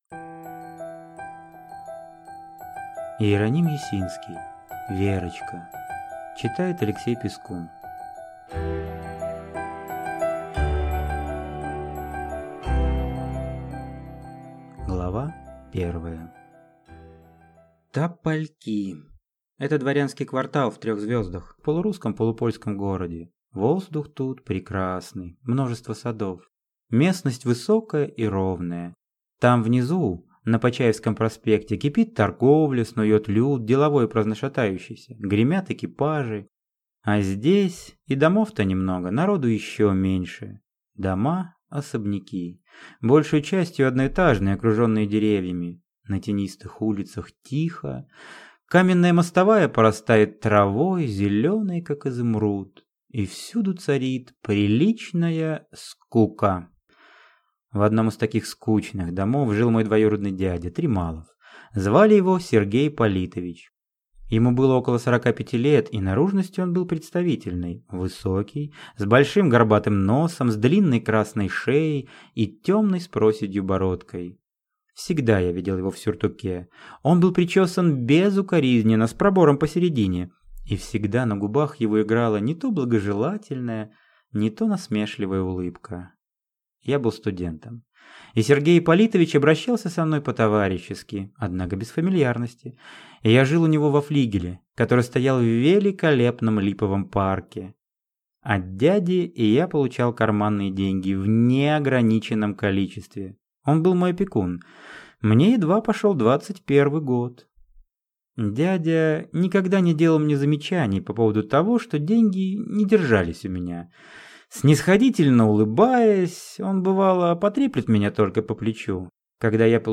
Аудиокнига Верочка | Библиотека аудиокниг